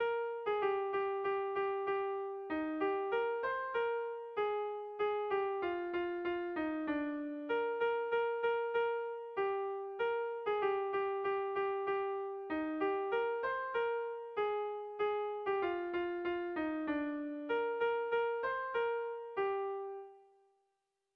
Irrizkoa
Doinuz lauko txikia da.
ABAB